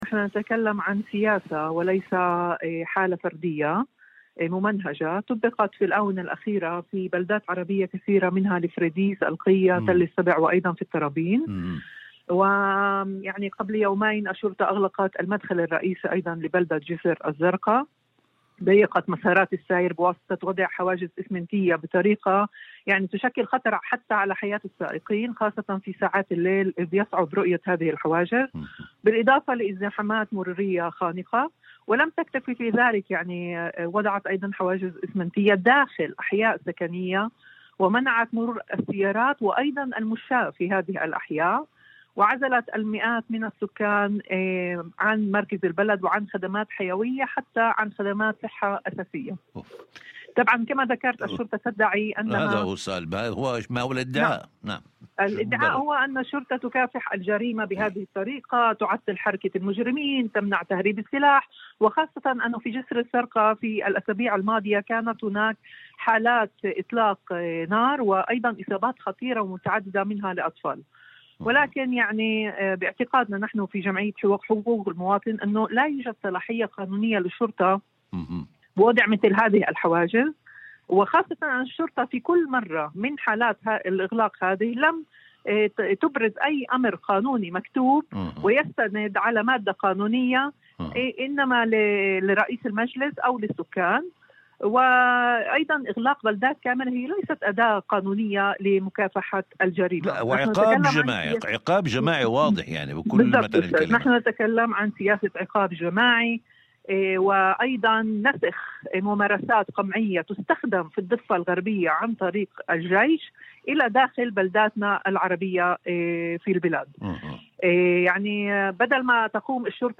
مداخلة هاتفية ضمن برنامج "أول خبر" على إذاعة الشمس